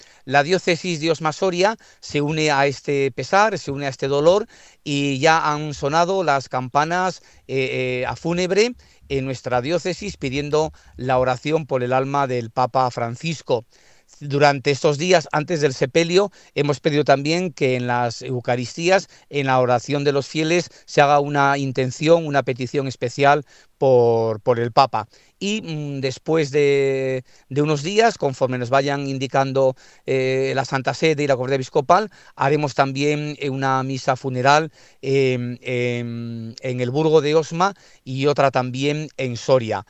OBISPO-actos-de-Osma-Soria.mp3